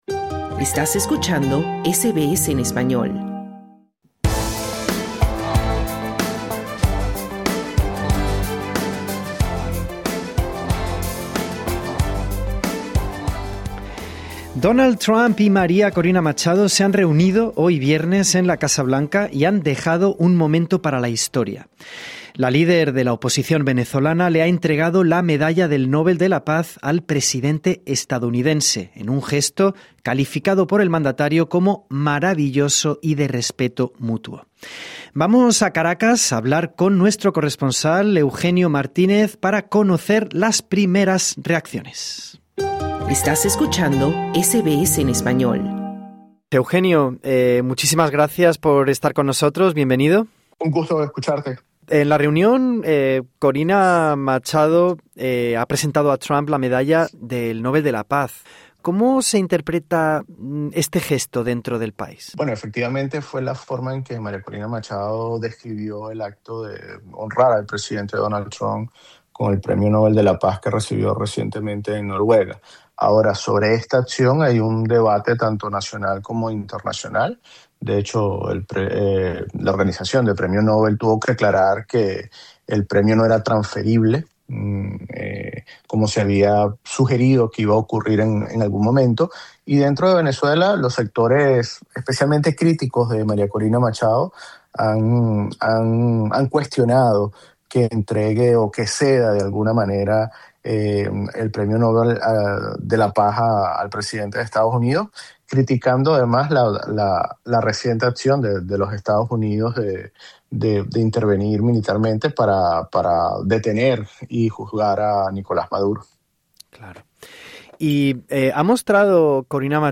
Nuestro corresponsal en Caracas explica cómo han sido las primeras reacciones después de la reunión de la líder opositora venezolana y el mandatario estadounidense en la Casa Blanca. El optimismo de Corina Machado tras el encuentro es comedido entre sus seguidores dentro del país, mientras Delsy Rodríguez pide a la Asamblea Nacional que cambie la legislación para que las petroleras estadounidenses puedan operar.